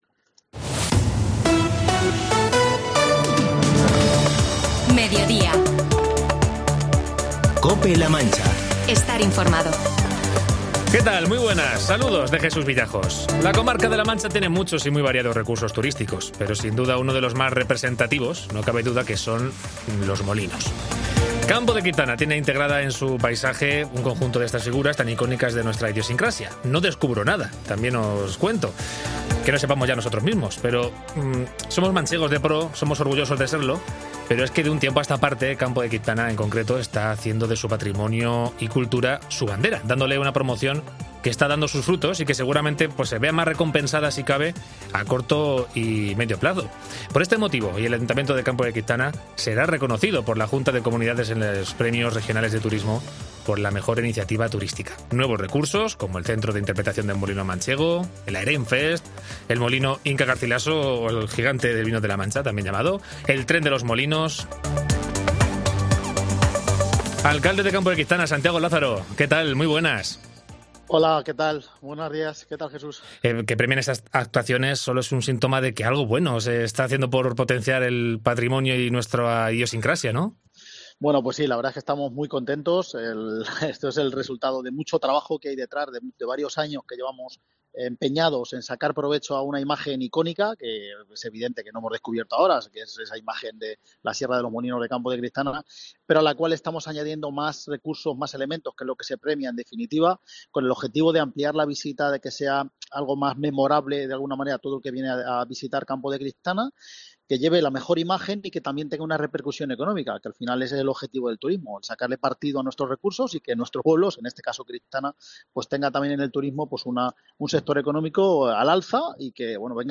Entrevista al alcalde de Campo de Criptana, Santiago Lázaro, cuyo ayuntamiento recibirá el Premio a la Mejor Iniciativa Turística de la Junta de Comunidades de C-LM